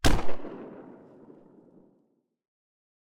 revolver-single-fire-001-01.ogg